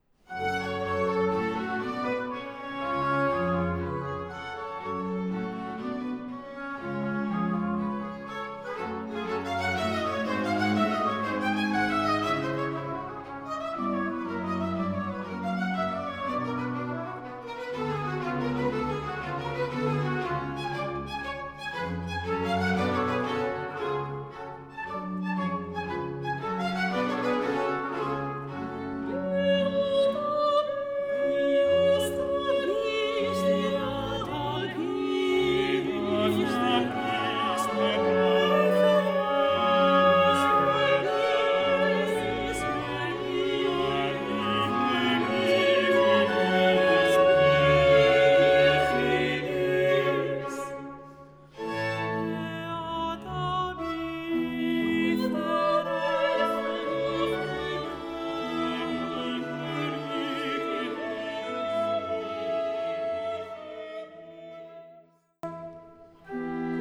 una de las obras de referencia del Barroco musical español.